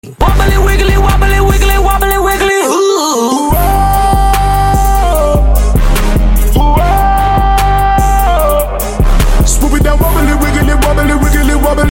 Wobbly wiggly sound effects free download
You Just Search Sound Effects And Download. tiktok comedy sound effects mp3 download Download Sound Effect Home